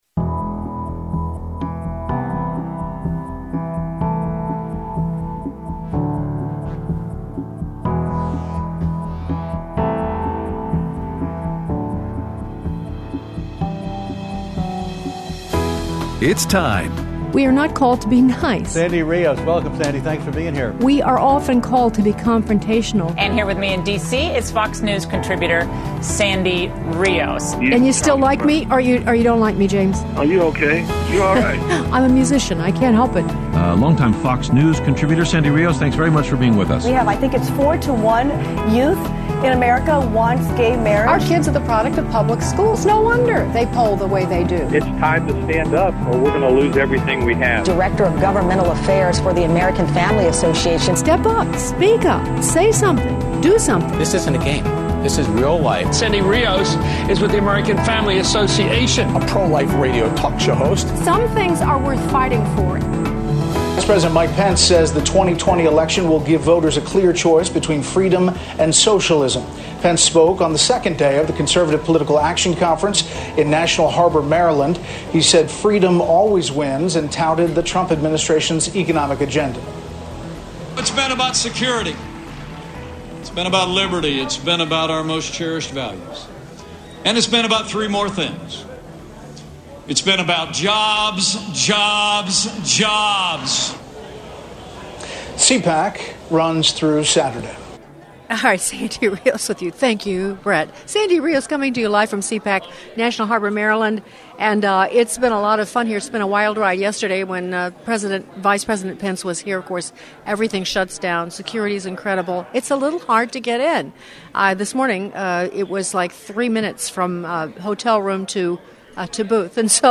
Live From CPAC 2020 With Fred Fleitz On National Security and Dave Brat on Economy Including Response to Coronavirus